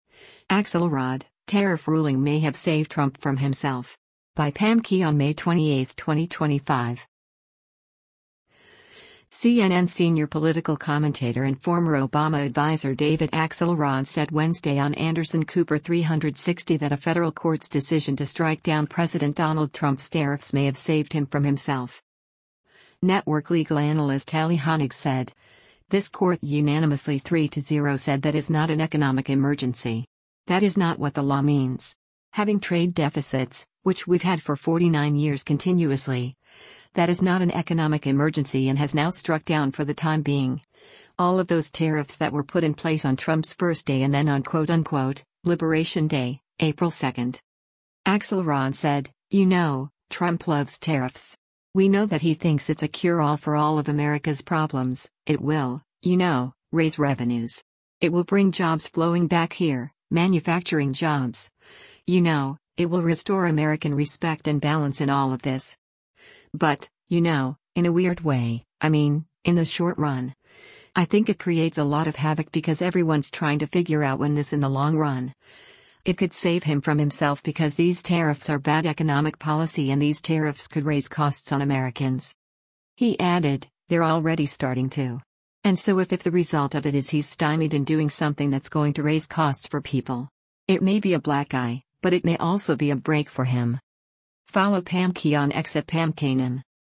CNN senior political commentator and former Obama adviser David Axelrod said Wednesday on “Anderson Cooper 360” that a federal court’s decision to strike down President Donald Trump’s tariffs may have saved him from himself.